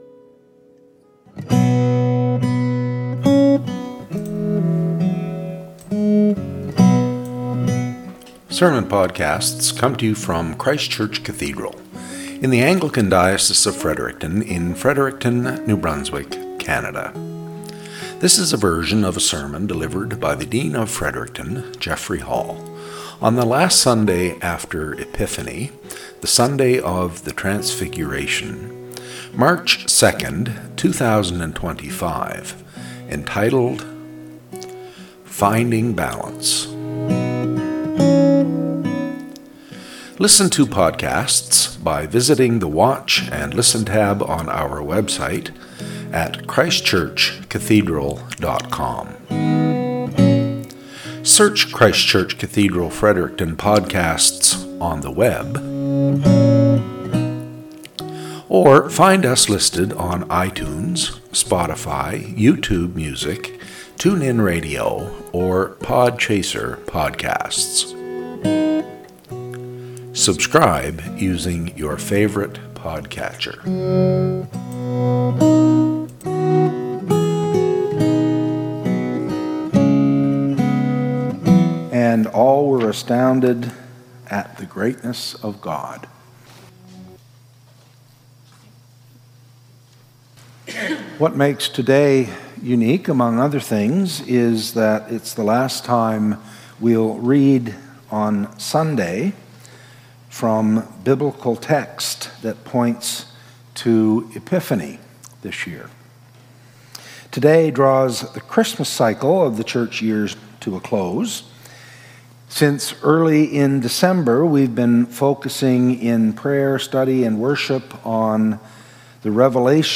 SERMON - "Finding Balance"